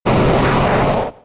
Cri de Tortank K.O. dans Pokémon X et Y.